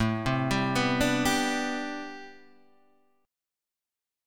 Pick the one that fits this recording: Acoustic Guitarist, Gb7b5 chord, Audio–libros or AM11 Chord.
AM11 Chord